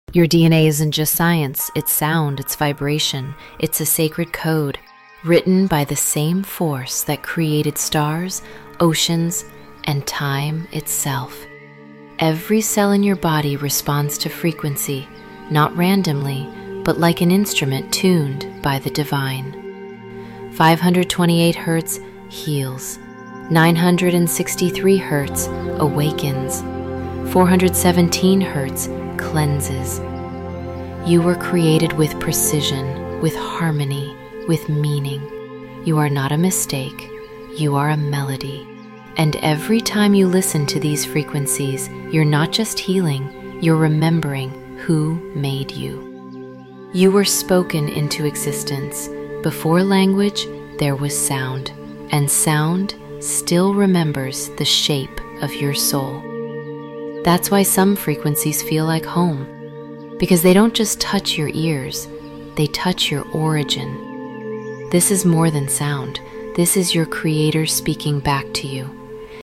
Let this frequency remind your cells who created them.